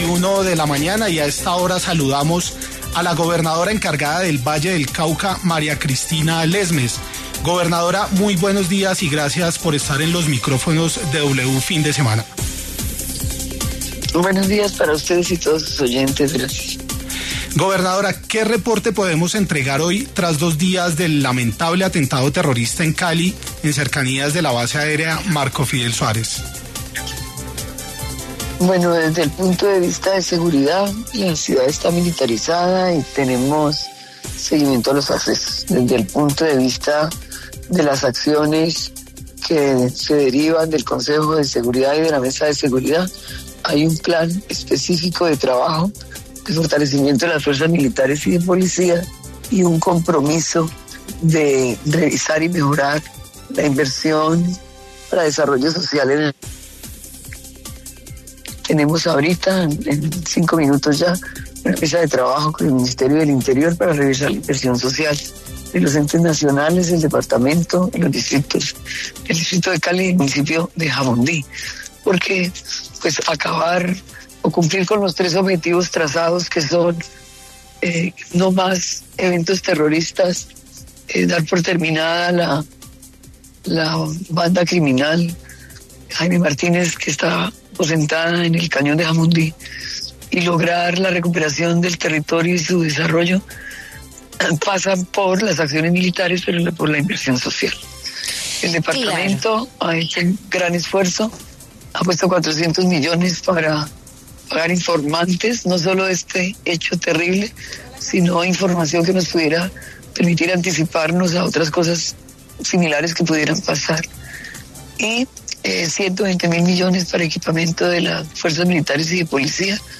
María Cristina Lesmes, gobernadora encargada del Valle del Cauca pasó por los micrófonos de W Fin de Semana y habló sobre el atentado en inmediaciones de la Escuela de Aviación Marco Fidel Suárez de Cali, Valle del Cauca, que dejó 6 muertos.